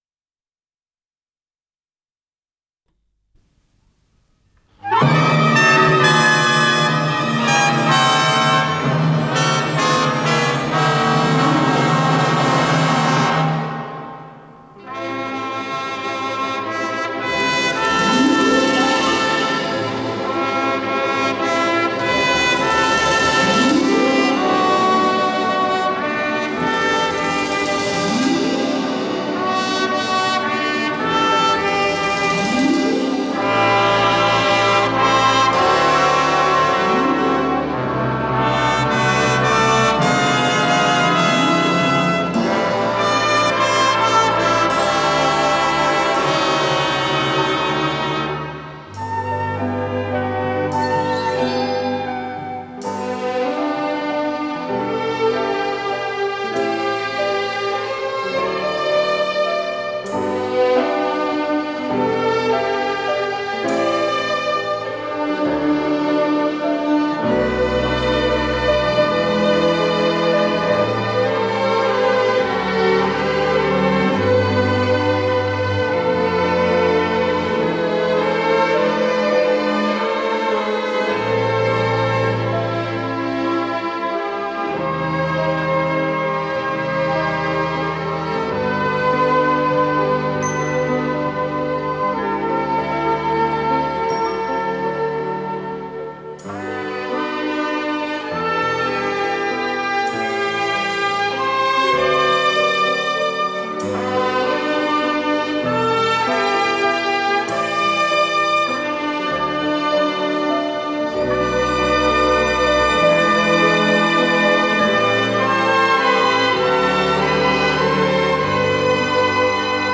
соль мажор
ВариантДубль моно